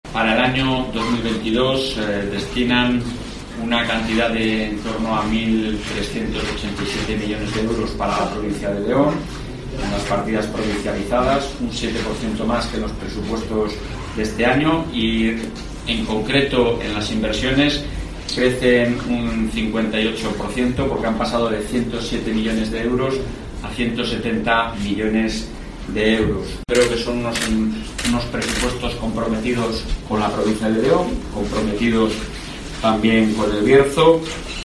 AUDIO: Escucha aquí las palabras del presidente del Gobierno regional